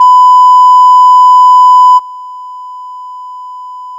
- 空気伝播音の場合 -
《基準壁》からの音 2秒 →《基準壁+ノイズクリア》からの音 2秒